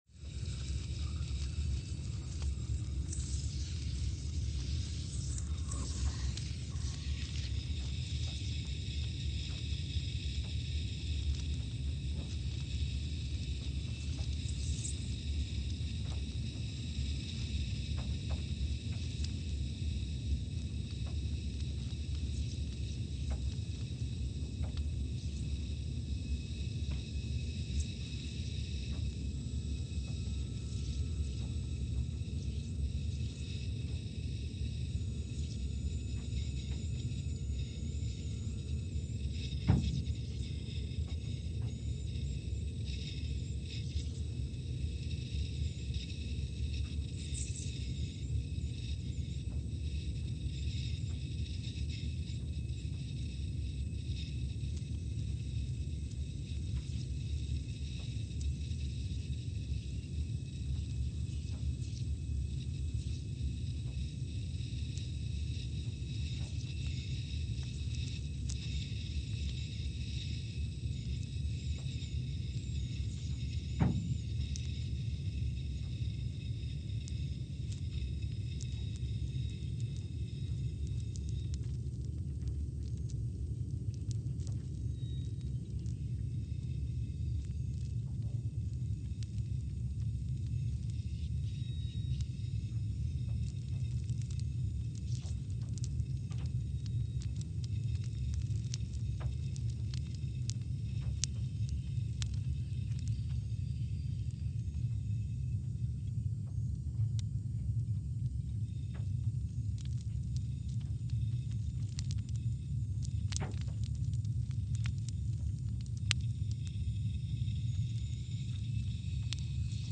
Scott Base, Antarctica (seismic) archived on November 9, 2020
Station : SBA (network: IRIS/USGS) at Scott Base, Antarctica
Speedup : ×500 (transposed up about 9 octaves)
SoX post-processing : highpass -2 90 highpass -2 90